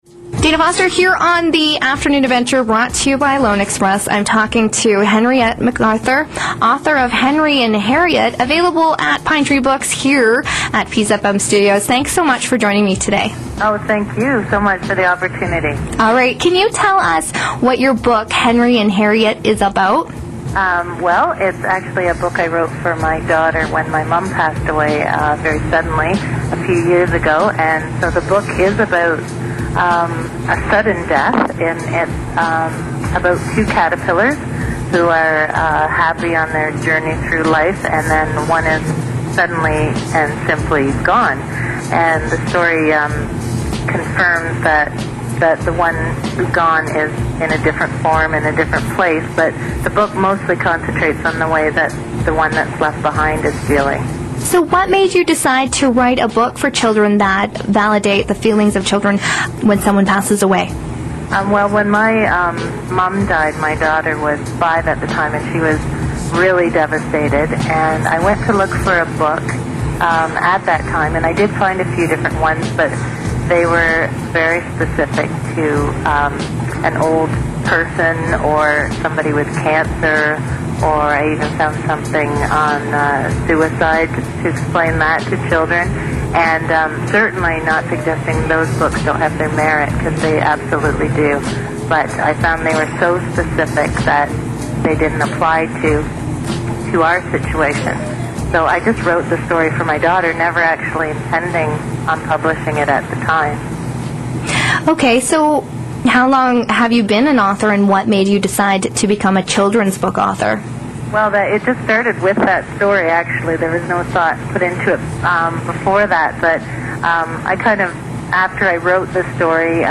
Live Radio interview